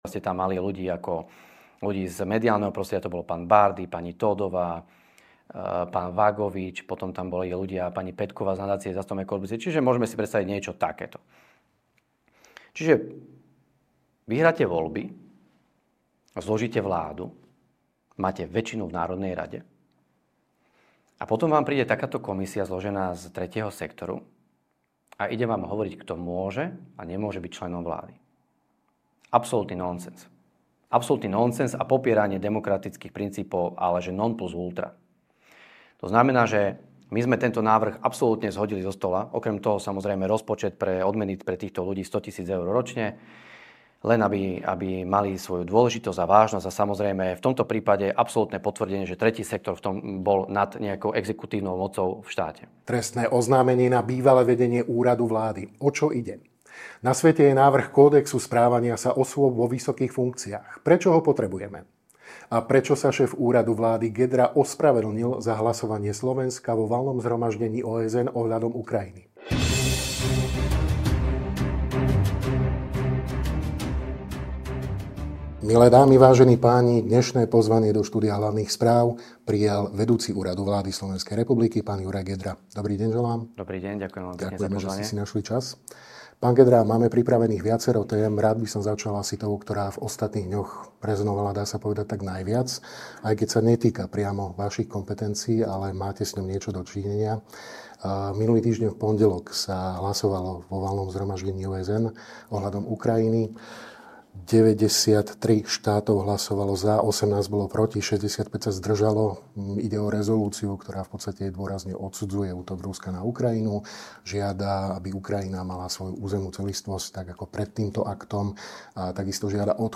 NAŽIVO